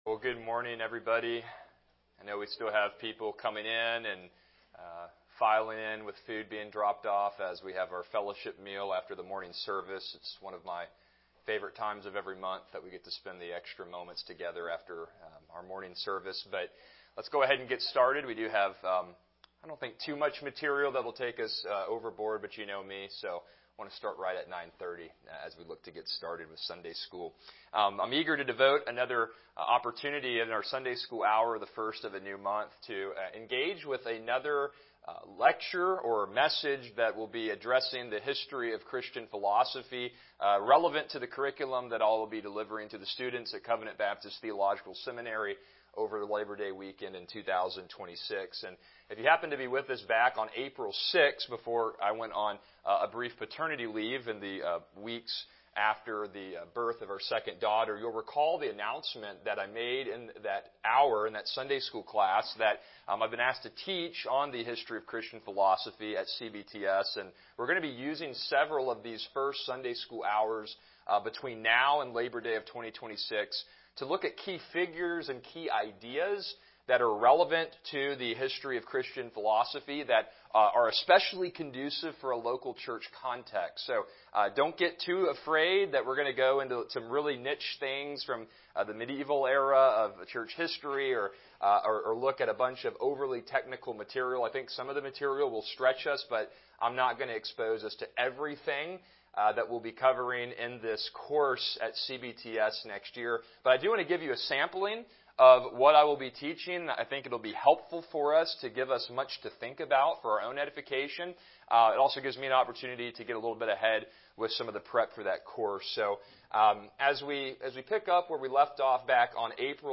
Series: Christianity and Philosophy Service Type: Sunday School